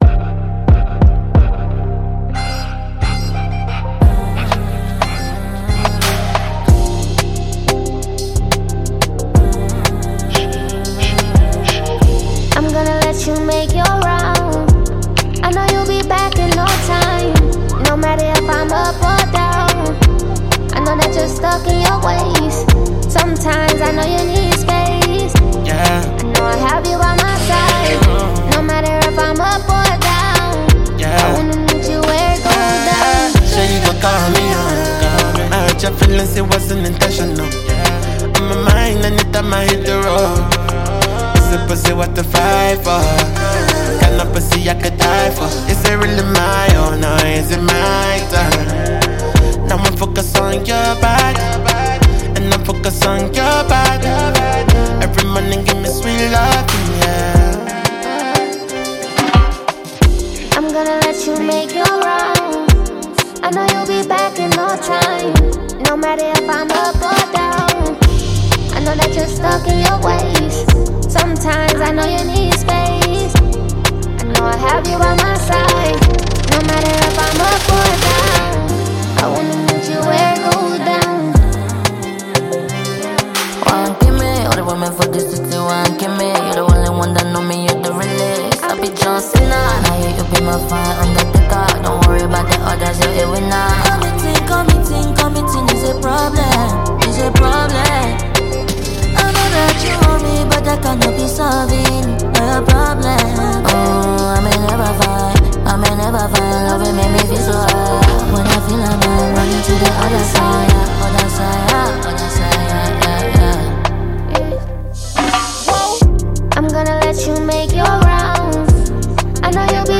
Popular American rapper
vocalist